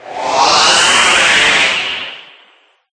Flash3.ogg